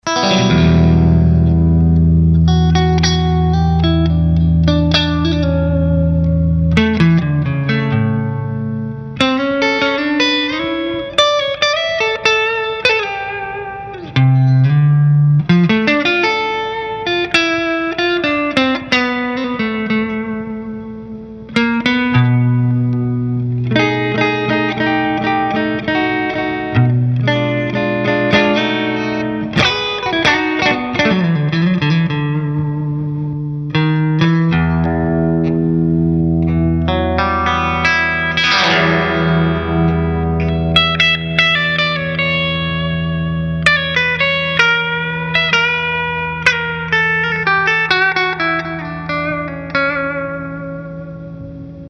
Inflames é um falante com voice britânico inspirado em um dos mais renomados timbres dessa linhagem, apresenta harmônicos extremamente detalhados e complexos, graves encorpados e definidos, alcance médio rico e detalhado com características do timbre usado por Slash, Steve Stevens e Peter Frampton.
CLEAN 2
Inflames_clean_2.mp3